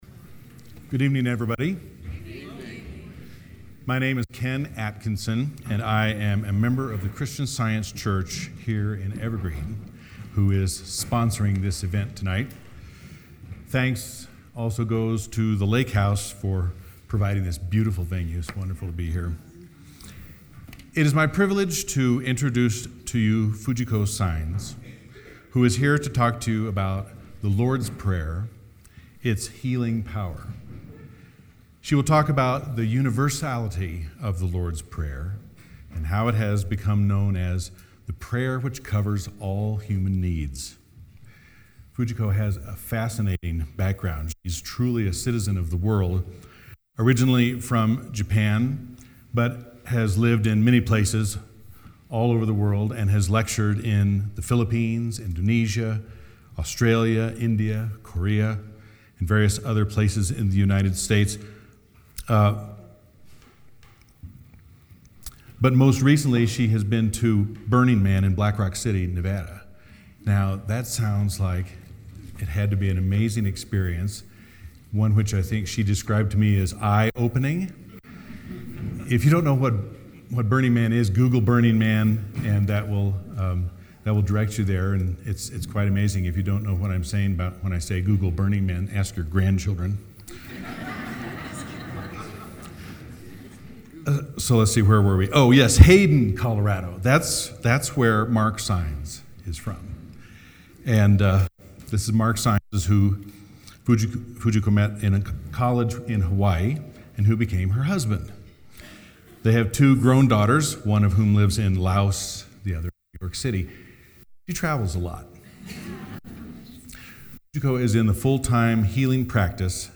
Lords-Prayer-Lecture.mp3